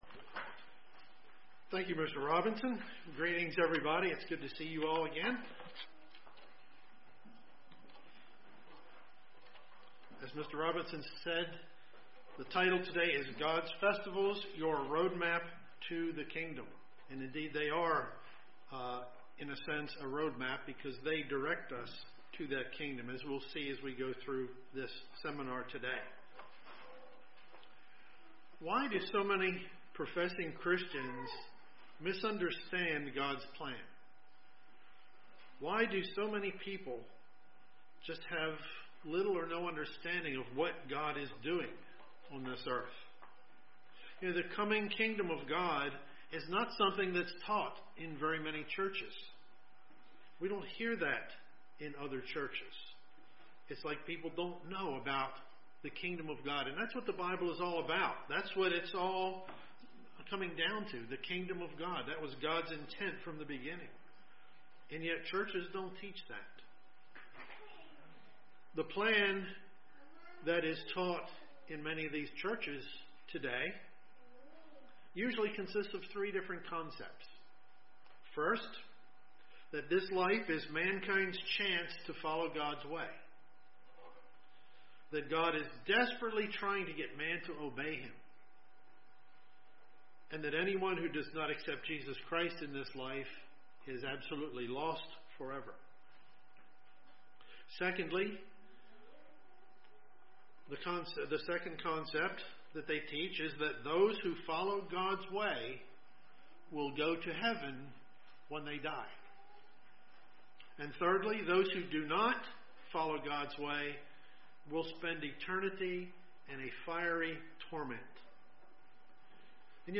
Part 1 of the Kingdom of God seminar that linked God's Kingdom to the Seven Holy Days given in Leviticus 23.
Given in Columbia, MD
UCG Sermon Studying the bible?